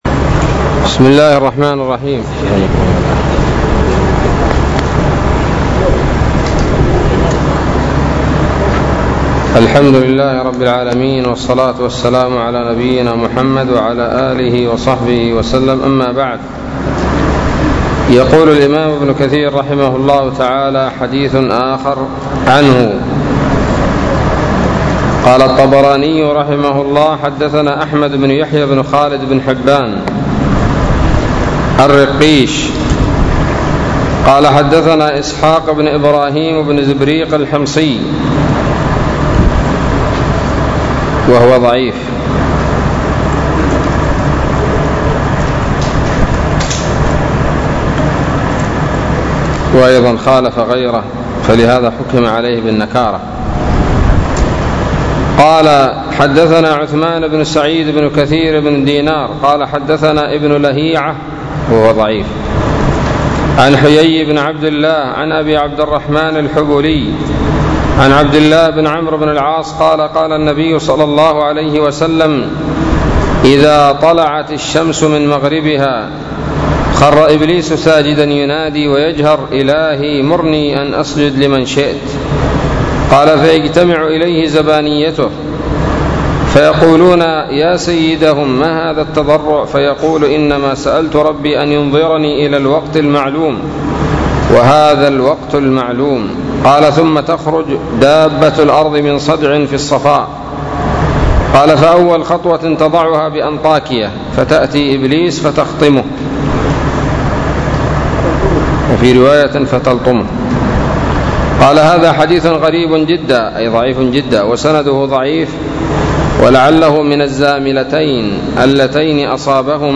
الدرس الثالث والسبعون من سورة الأنعام من تفسير ابن كثير رحمه الله تعالى